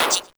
bluePop.ogg